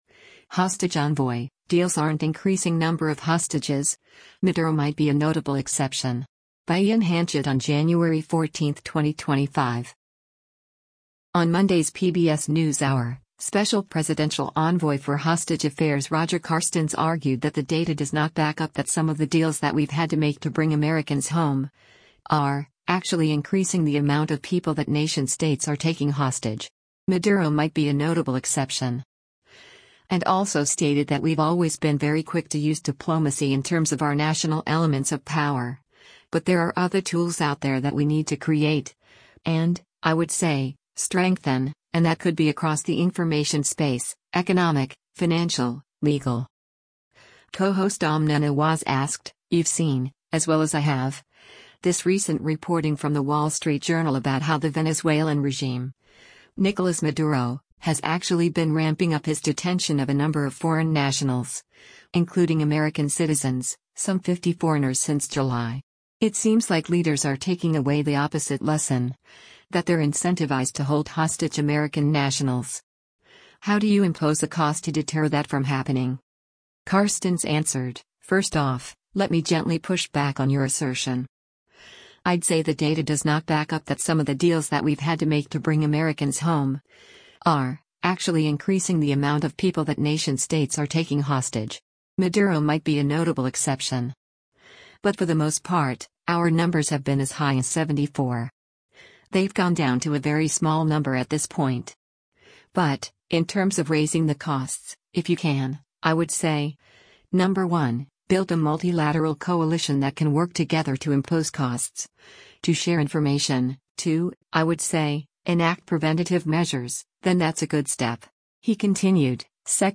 On Monday’s “PBS NewsHour,” Special Presidential Envoy for Hostage Affairs Roger Carstens argued that “the data does not back up that some of the deals that we’ve had to make to bring Americans home [are] actually increasing the amount of people that nation-states are taking hostage. Maduro might be a notable exception.”